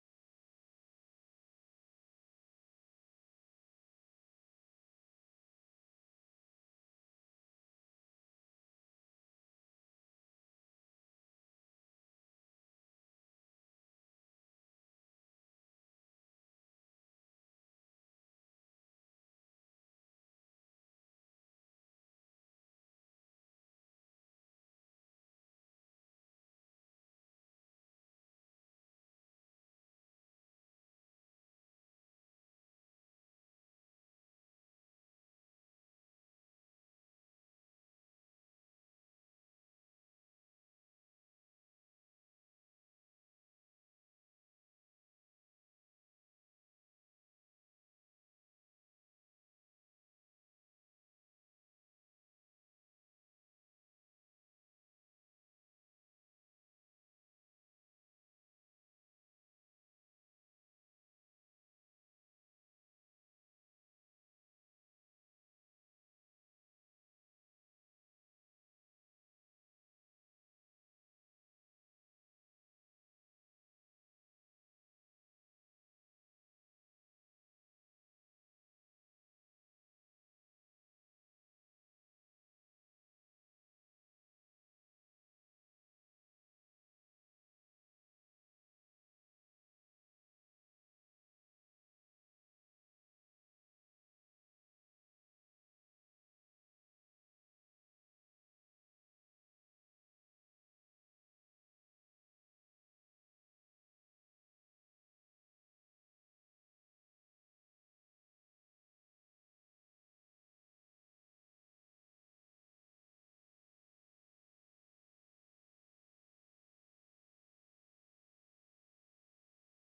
September 17 2023 Sunday Worship